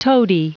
Prononciation du mot toady en anglais (fichier audio)
Prononciation du mot : toady